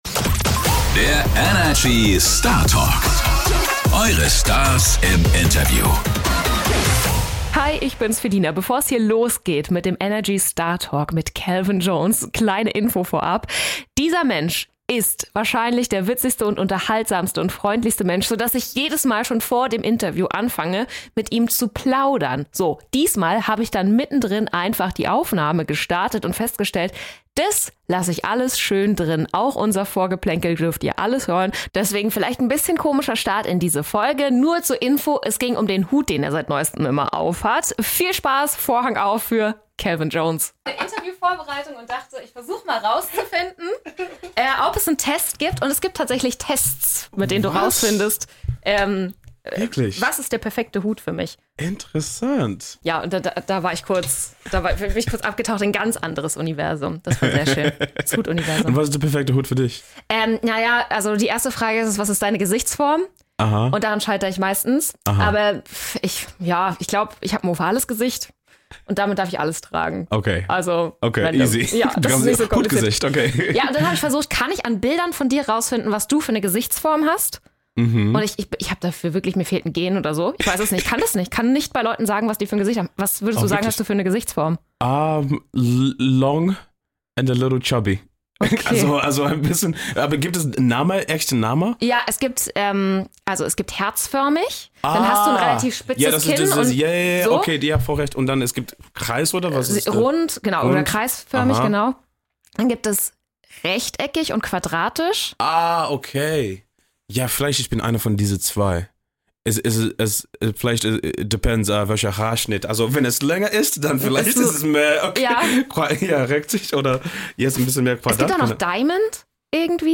Er kommt ins Studio und bringt die Sonne mit: Kelvin Jones! Herrlich selbstironisch, immer eine gute Story parat und unglaublich lustig – so erlebt ihr den Wahl-Berliner in dieser Startalk-Folge. Wir haben mit ihm über Hüte gesprochen, über seine Familie und darüber, was Erfolg für ihn bedeutet.